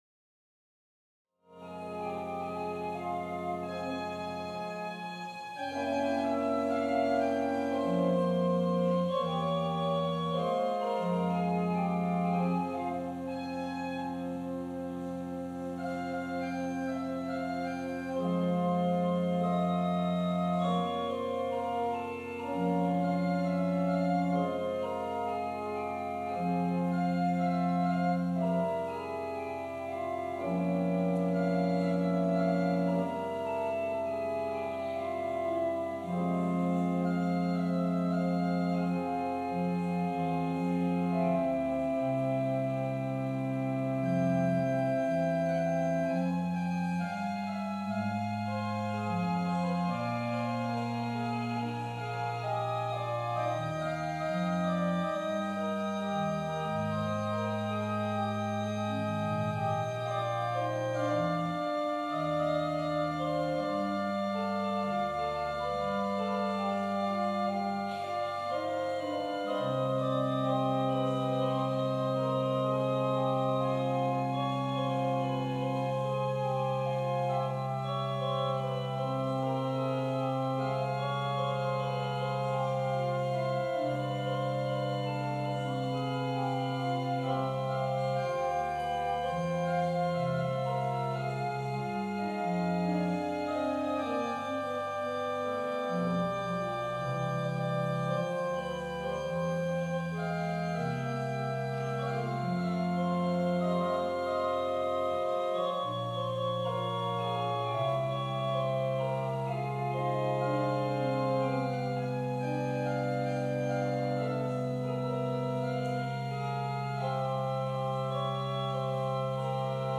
Passage: Luke 12: 32:40 Service Type: Sunday Service Scriptures and sermon from St. John’s Presbyterian Church on Sunday